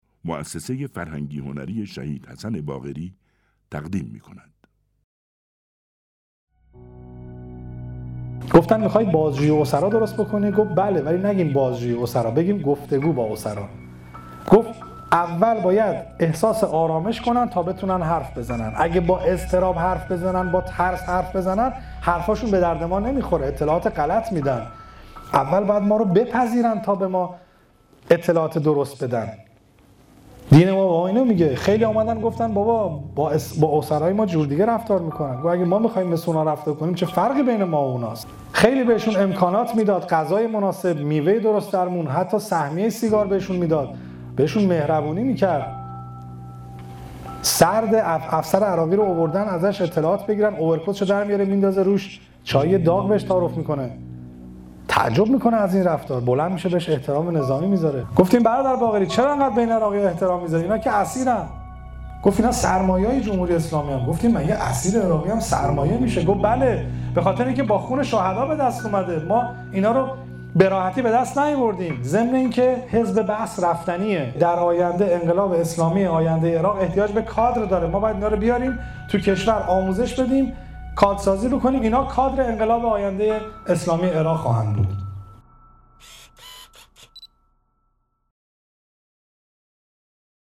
روایتگری از حسن باقری درباره اسرای عراقی در یادمان شهید حسن باقری